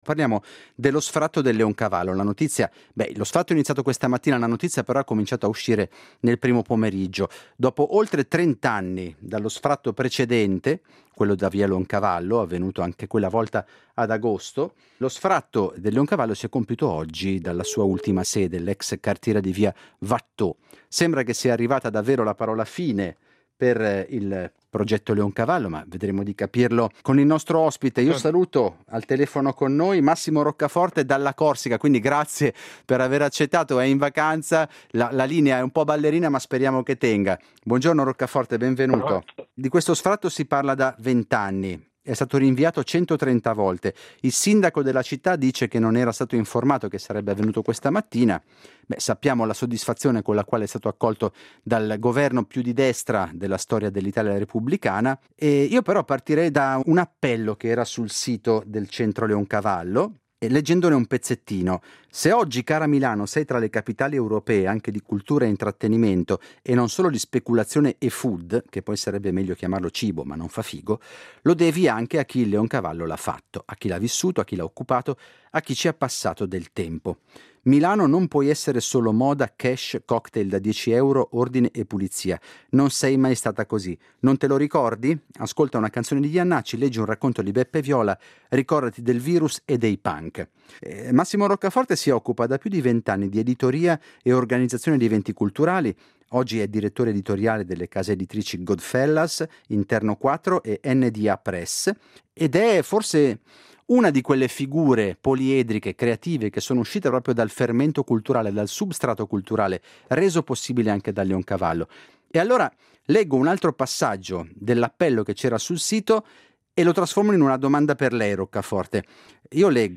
conversazione